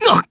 Clause-Vox_Damage_jp_01.wav